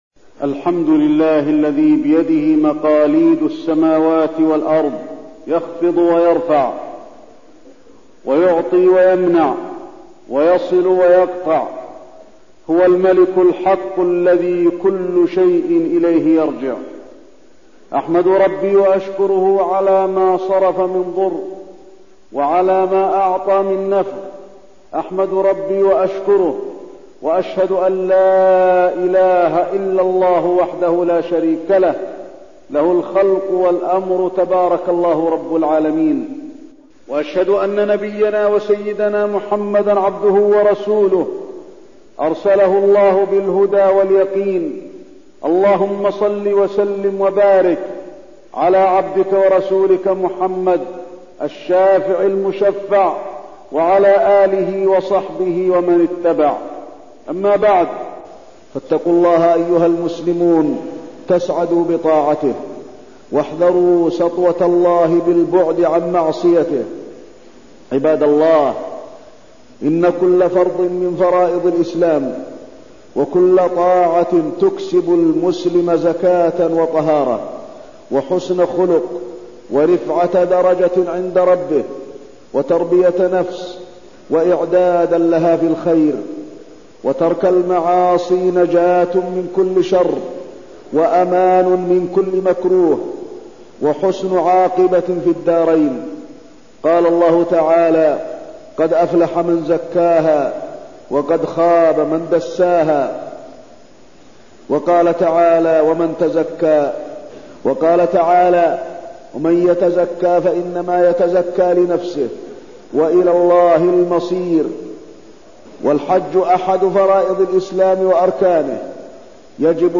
خطبة الحج وفيها: الحكمة من مشروعية الحج، أهمية تطييب نفقة الحج، بعض أنواع الجهاد التي تتحقق من الحج، وجزاء الحج المبرور
تاريخ النشر ٣٠ ذو القعدة ١٤١٣ المكان: المسجد النبوي الشيخ: فضيلة الشيخ د. علي بن عبدالرحمن الحذيفي فضيلة الشيخ د. علي بن عبدالرحمن الحذيفي الحج